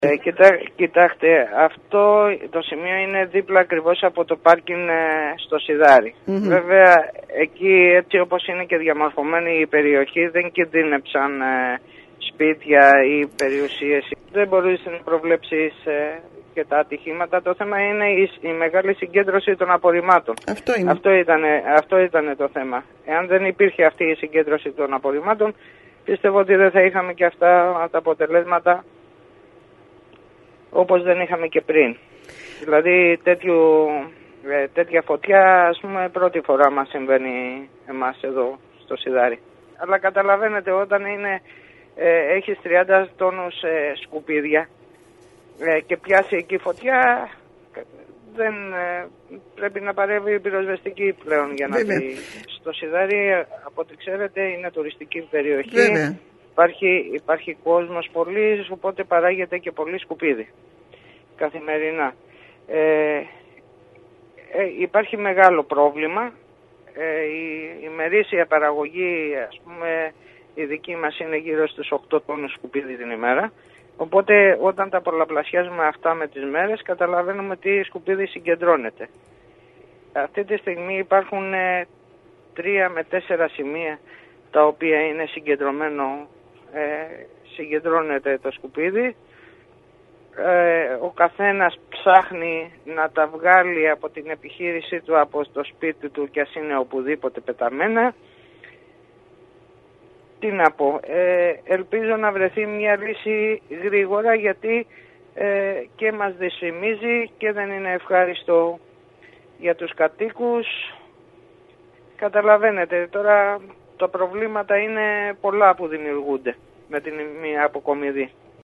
Μιλώντας στην ΕΡΤ Κέρκυρας ο πρόεδρος του τοπικού συμβουλίου Σιδαρίου κ. Σκέμπρης υπογράμμισε πως η κατάσταση με τα σκουπίδια στην ευρύτερη περιοχή έχει φτάσει σε οριακά σημεία